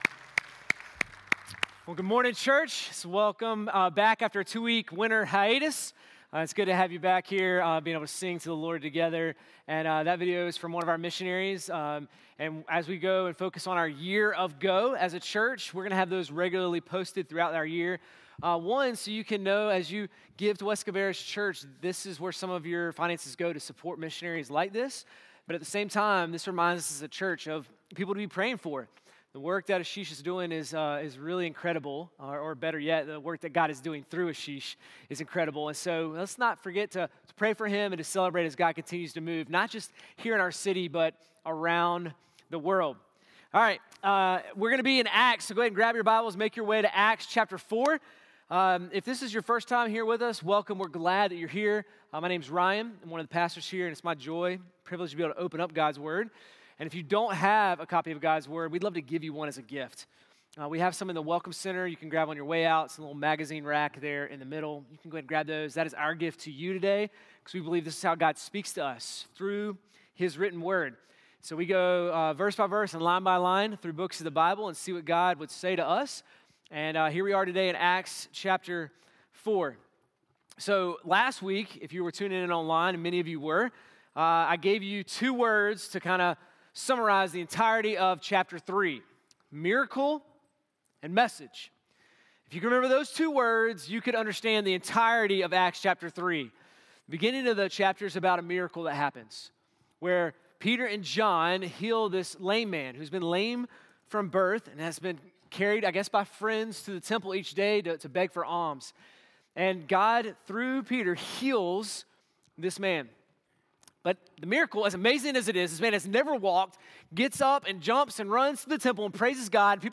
sermon-2-8-26.mp3